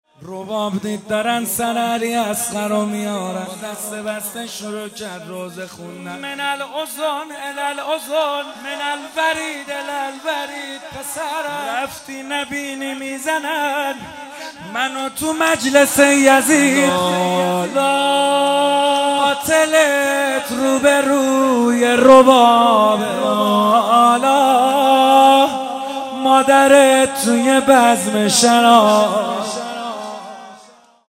مداحی محمدحسین حدادیان | ایام فاطمیه 1441 | محفل عزاداران حضرت زهرا (س) شاهرود | پلان 3